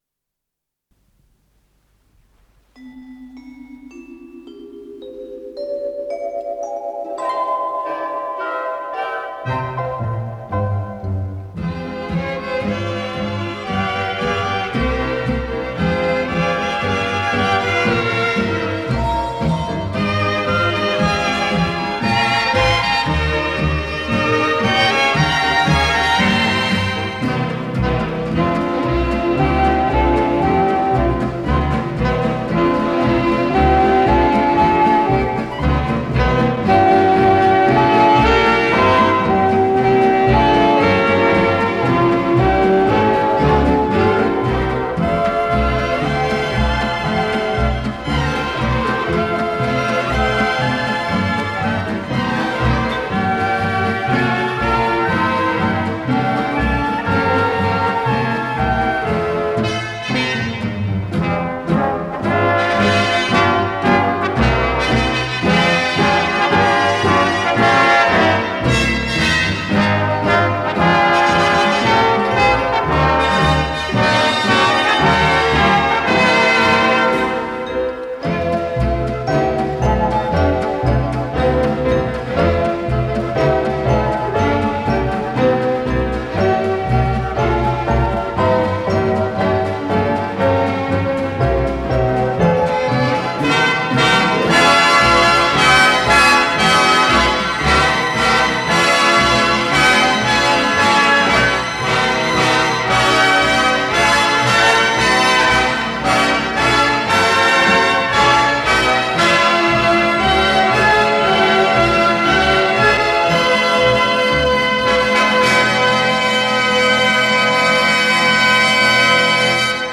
с профессиональной магнитной ленты
ПодзаголовокЛя бемоль мажор
Скорость ленты38 см/с
ВариантМоно